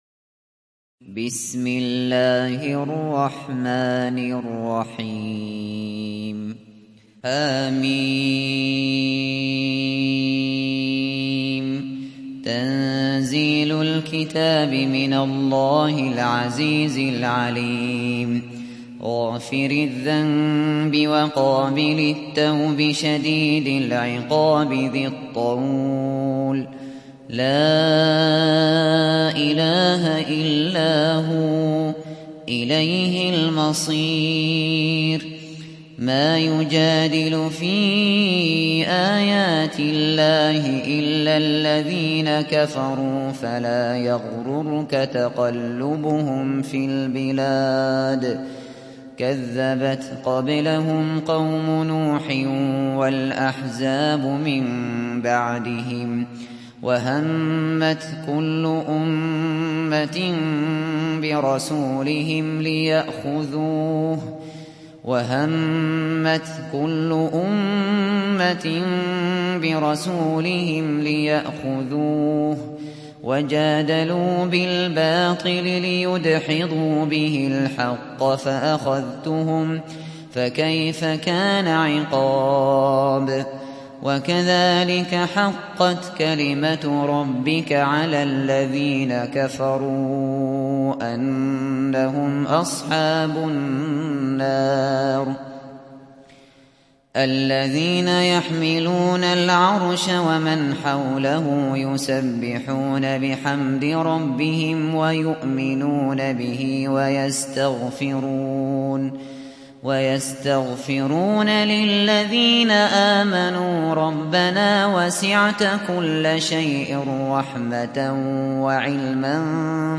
সূরা গাফের ডাউনলোড mp3 Abu Bakr Al Shatri উপন্যাস Hafs থেকে Asim, ডাউনলোড করুন এবং কুরআন শুনুন mp3 সম্পূর্ণ সরাসরি লিঙ্ক